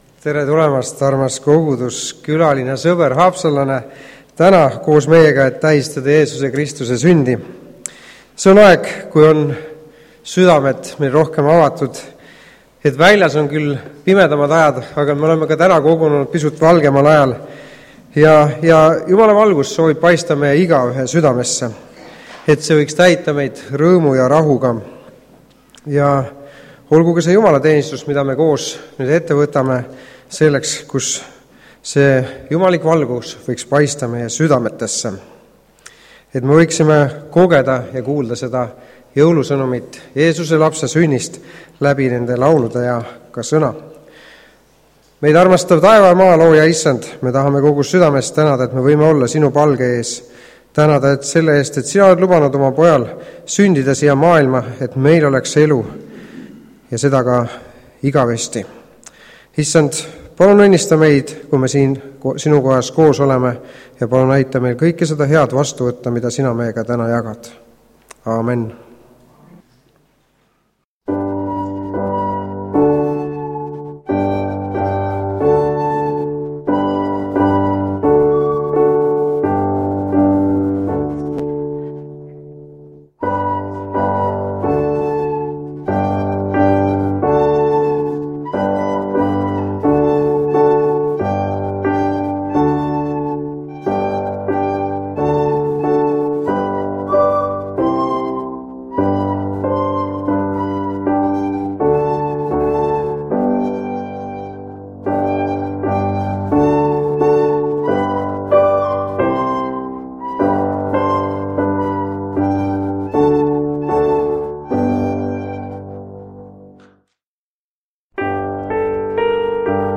JÕULUTEENISTUS (Haapsalus)
Koosolekute helisalvestused